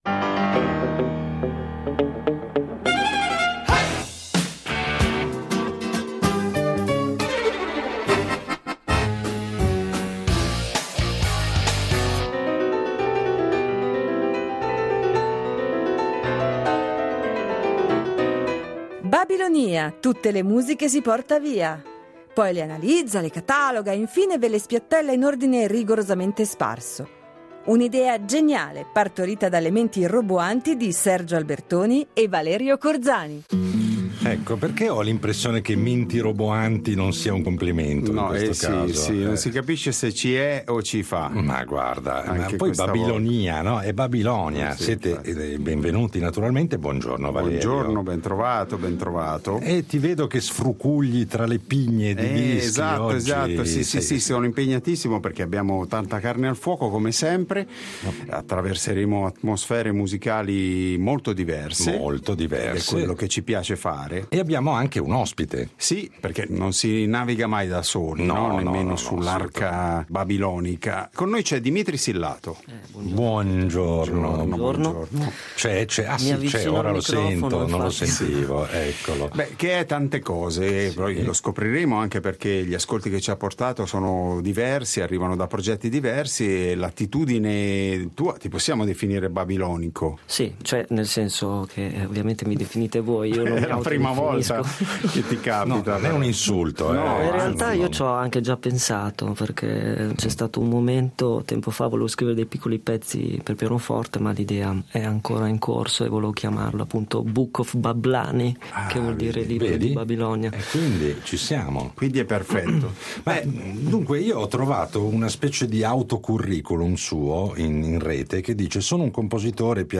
Il nostro ospite di oggi è un violinista, pianista e compositore originario di Massa Lombarda.